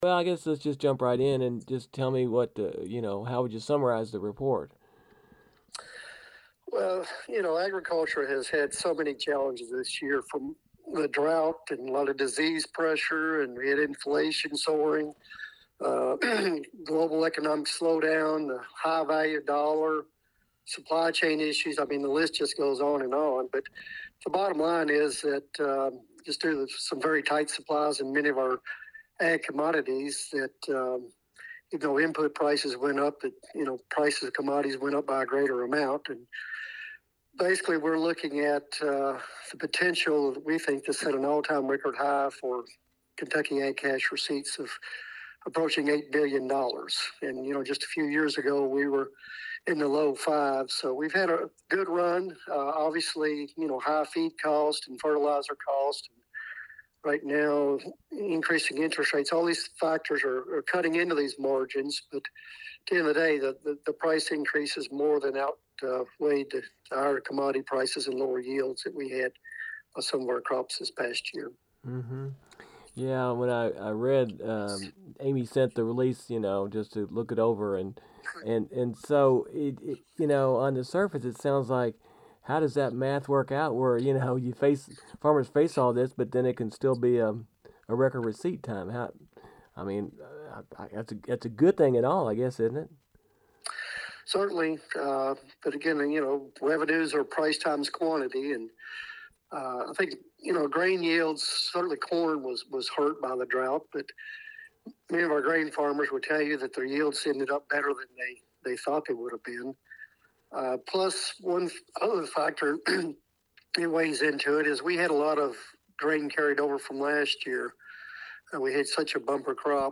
extended interview